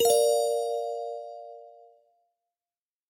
Звуки уведомления чата
Звук push-уведомления при всплывании окна